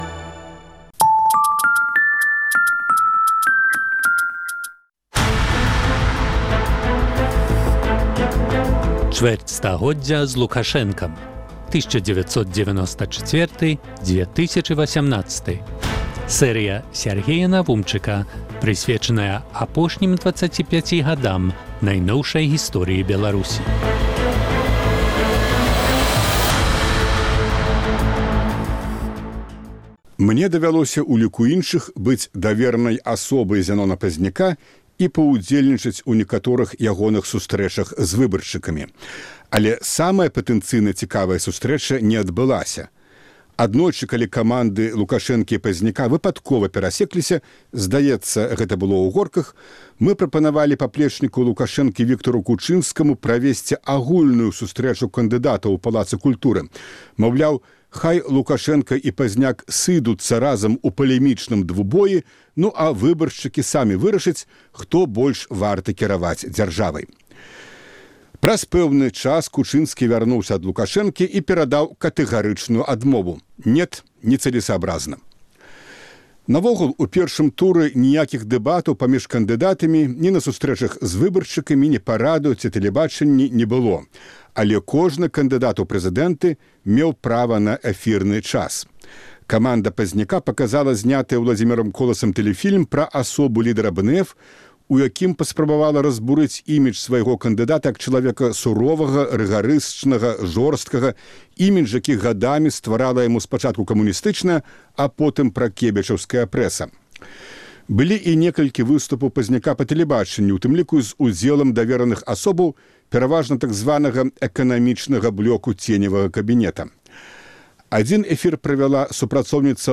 Перадвыбарчы тэлевыступ каманды Зянона Пазьняка